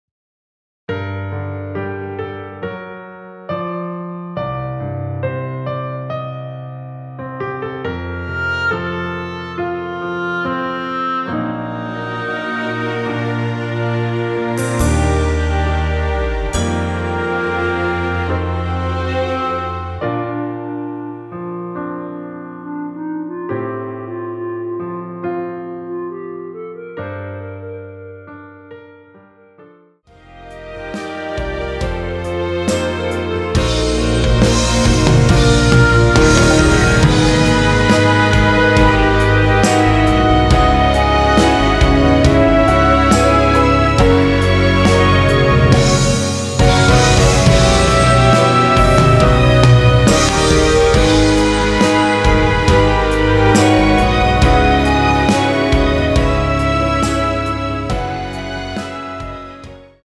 남성분이 부르실수 있는키로 제작하였습니다.
원키에서(-7)내린 멜로디 포함된 MR입니다.(미리듣기 참조)
Bb
앞부분30초, 뒷부분30초씩 편집해서 올려 드리고 있습니다.